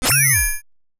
Holographic UI Sounds 87.wav